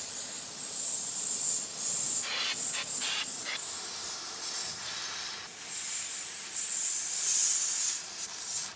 blowmetal.mp3